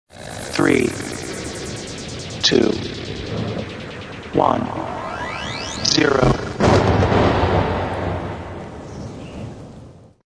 Kermis Jingle's  2013
Jingle-15-Countdown 3-0-.mp3